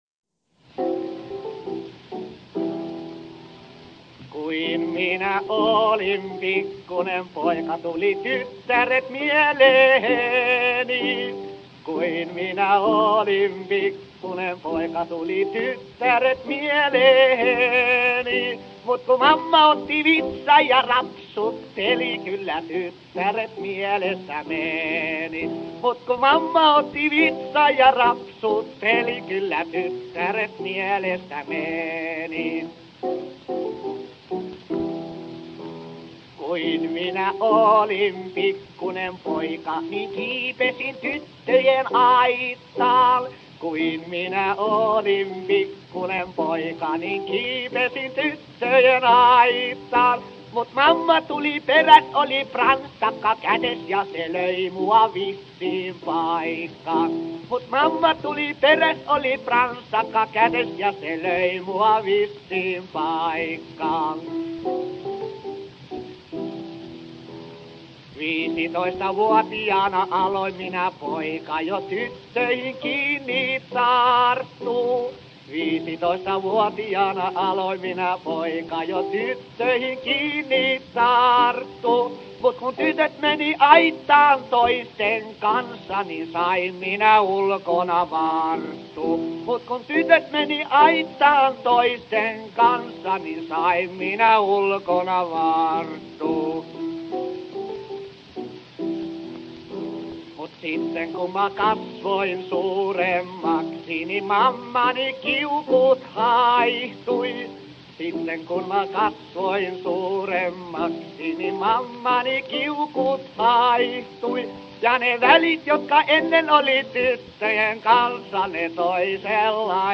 Einer der populärsten Künstler war der Coupletmeister J. Alfred Tanner (